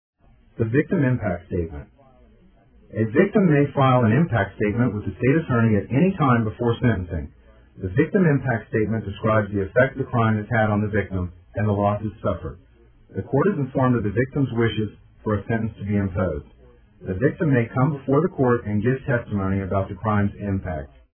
DUI Progression Described By a Board Certified Lawyer Go Over Each Step of a DUI in Tampa Courts